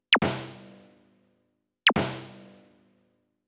ZAP LOFI S-R.wav